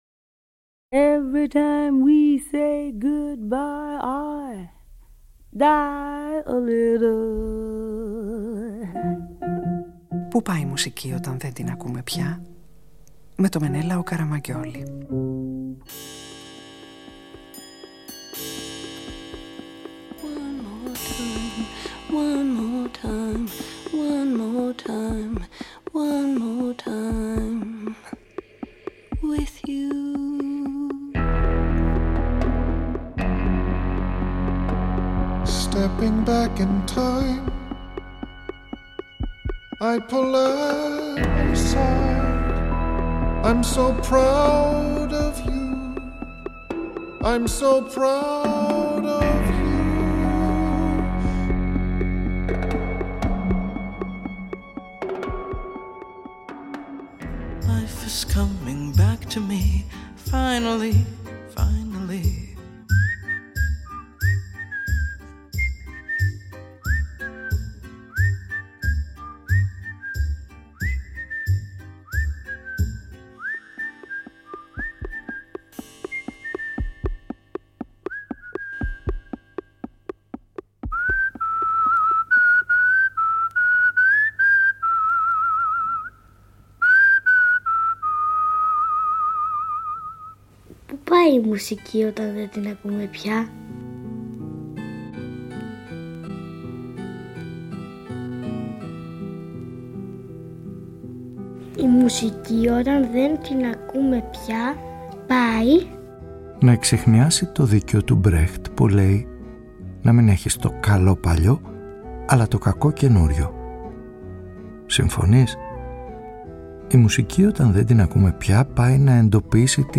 Ραδιοφωνικη Ταινια